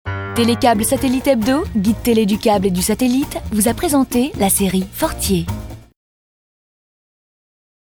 Naturel
Souriant
Voix off